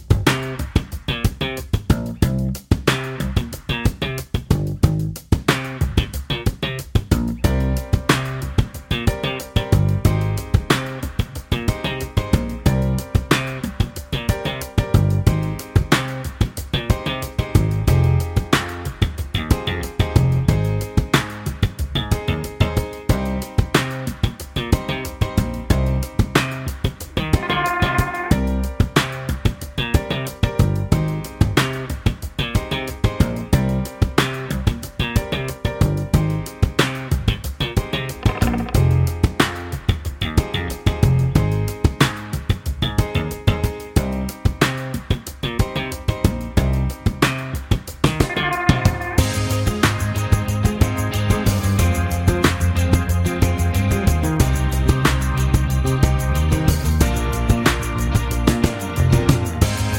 Minus Main Guitar For Guitarists 5:06 Buy £1.50